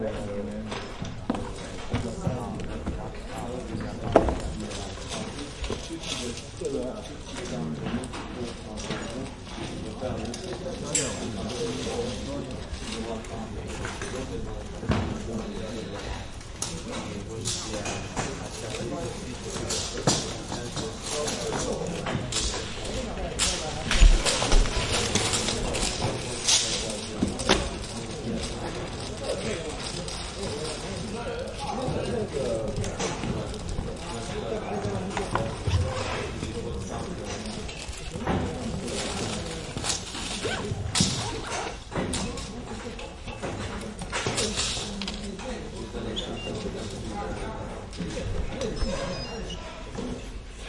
随机 " 剧院工作人员的舞台设置 魁北克语和普通话4好忙的运动